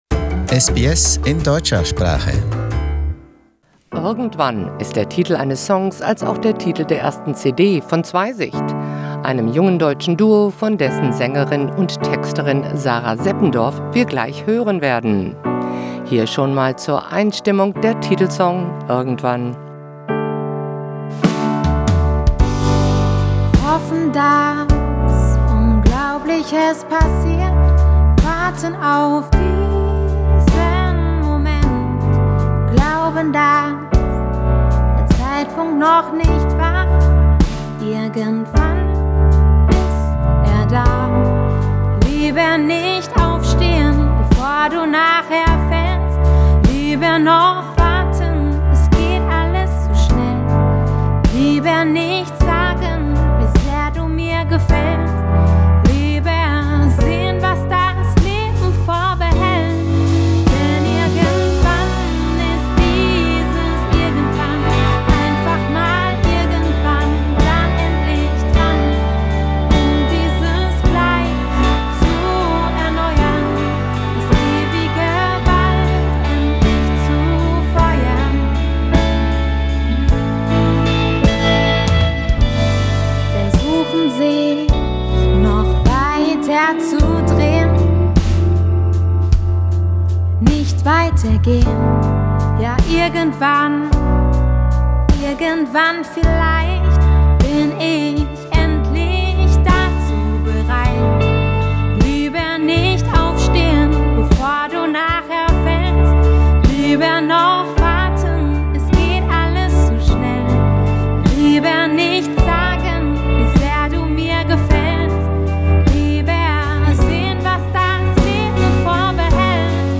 It is sophisticated pop music in the singer-songwriter style, with some rock, even some touches of rap.
We also hear several songs from the first CD of the duo, on which they play together with a band.